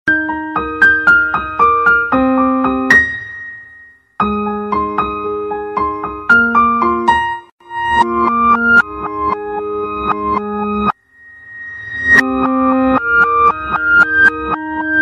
Reverse_Cute_SMS.mp3